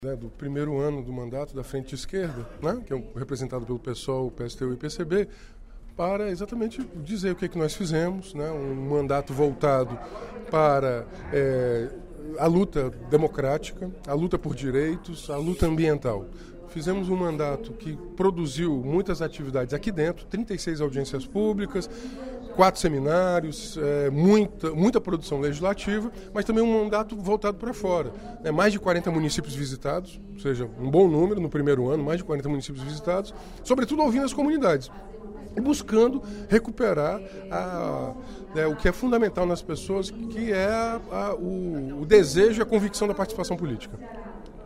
O deputado Renato Roseno (Psol) avaliou, durante o primeiro expediente da sessão plenária da Assembleia Legislativa desta sexta-feira (18/12), seu primeiro mandato na Casa.